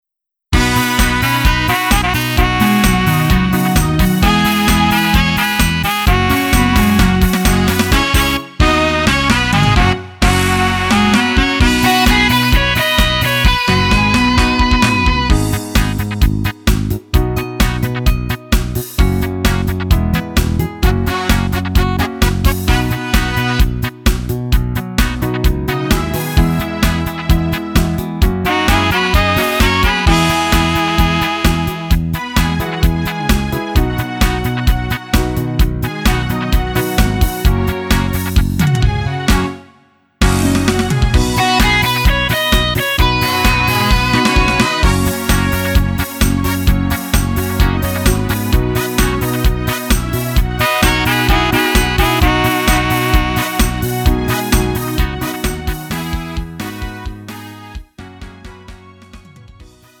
음정 -1키 3:15
장르 구분 Lite MR